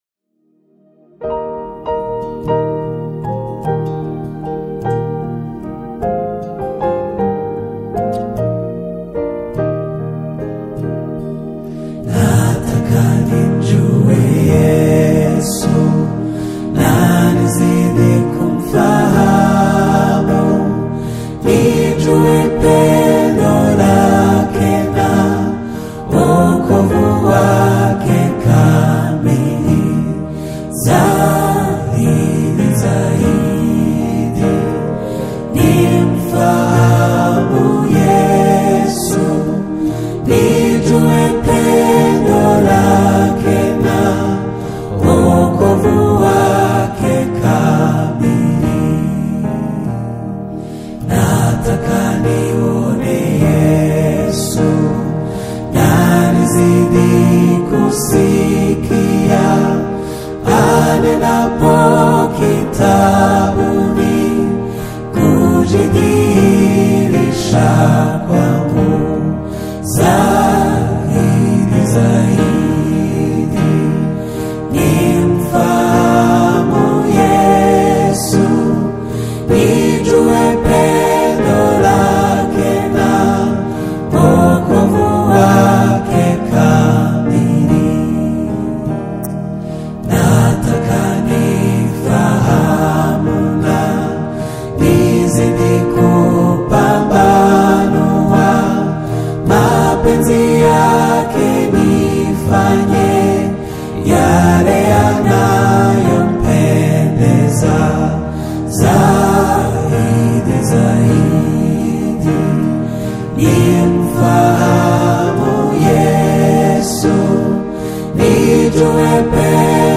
The acoustic-led and vocally shimmering rendition